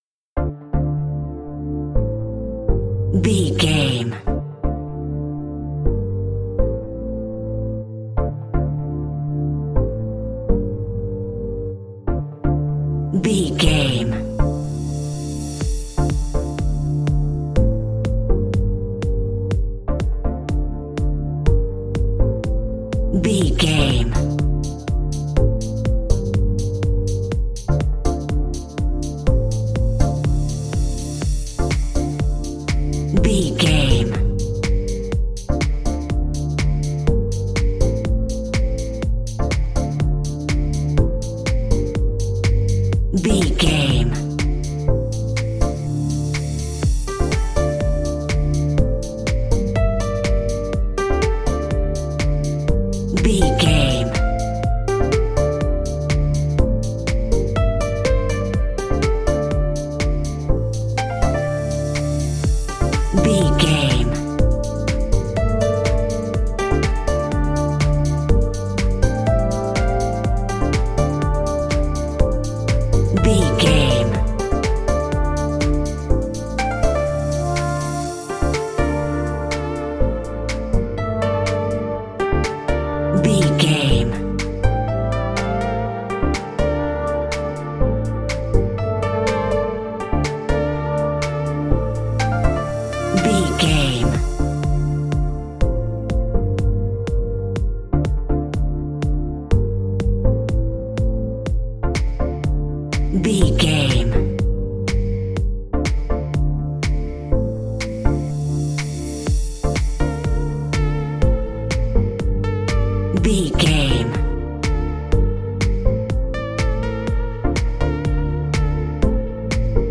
Aeolian/Minor
D
fun
groovy
uplifting
driving
energetic
synthesiser
drum machine
electric piano
techno
trance
synth bass